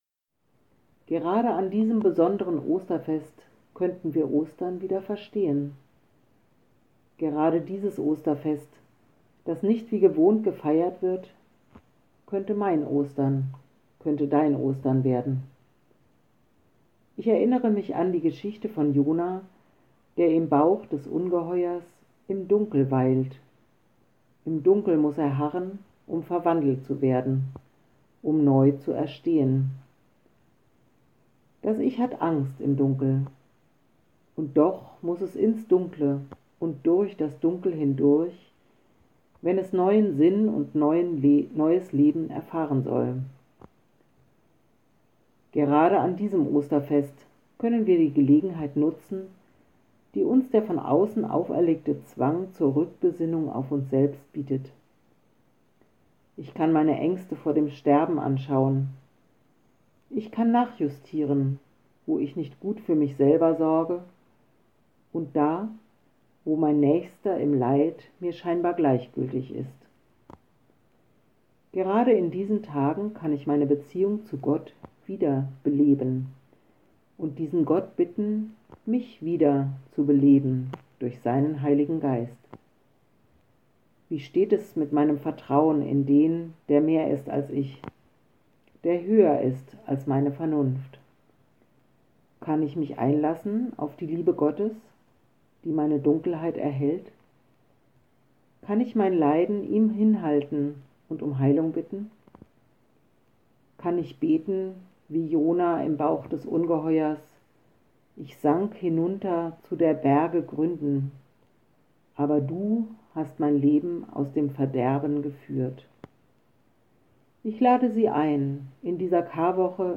Kurzandacht: Mein Ostern – Weinberggemeinde Berlin-Spandau